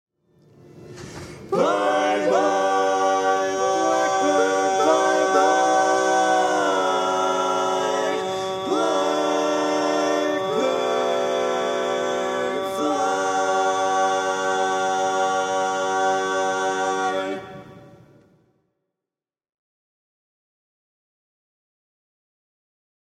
Key written in: G Major
Type: Barbershop